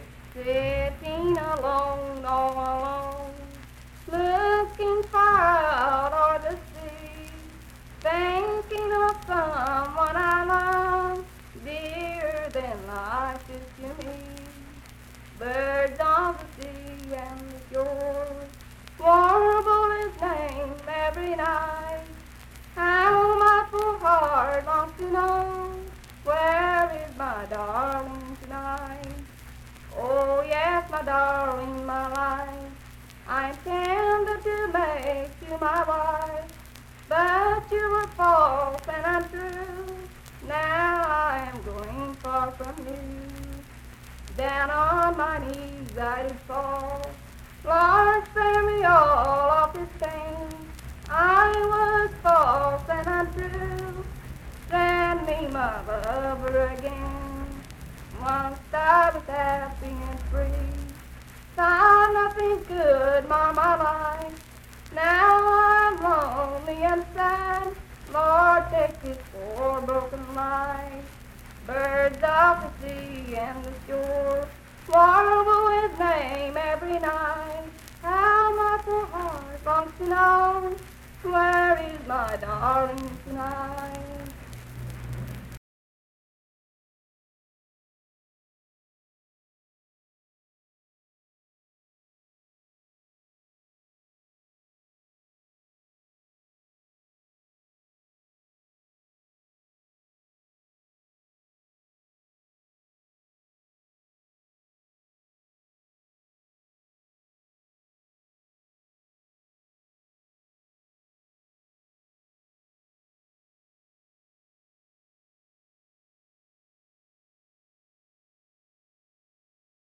Unaccompanied vocal performance
Voice (sung)
Roane County (W. Va.), Spencer (W. Va.)